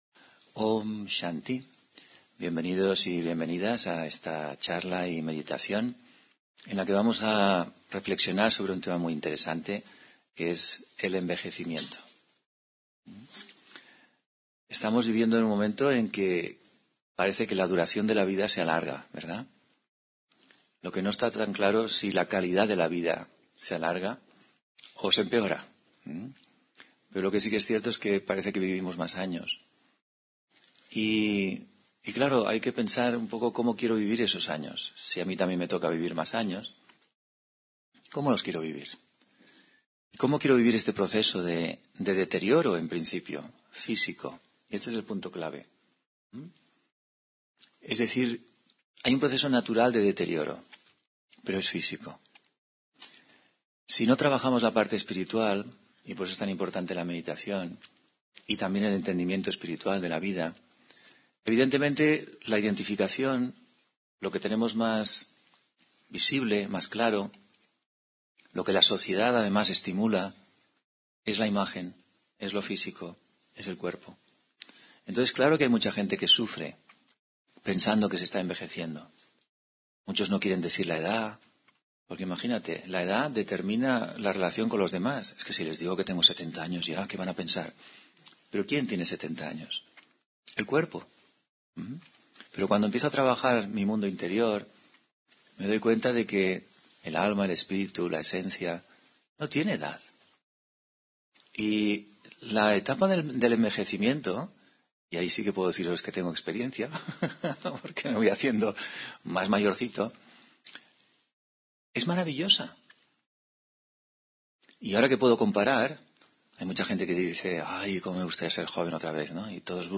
Meditación y conferencia: Sobre el envejecimiento (24 Enero 2025)